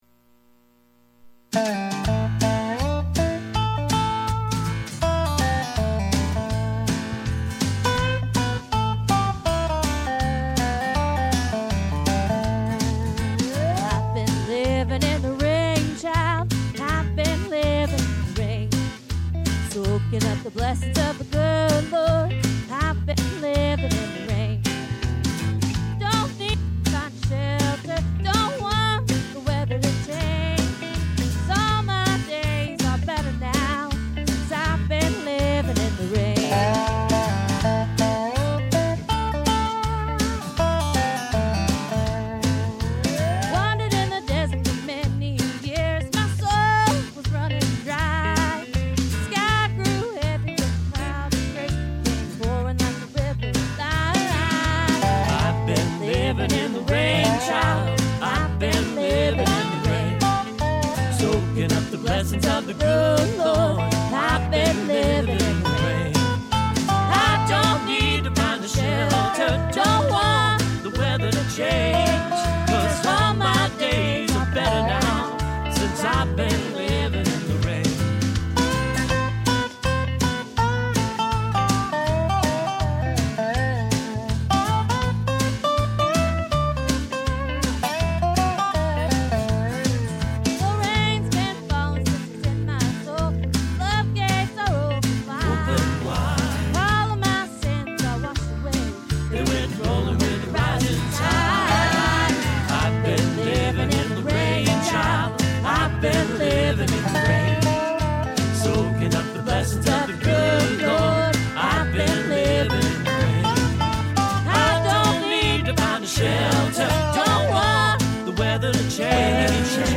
Bring Down Strongholds In Your Life-A.M. Service – Anna First Church of the Nazarene